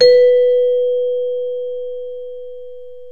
CELESTE B2.wav